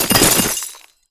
ice_spell_impact_icicle_hits4.wav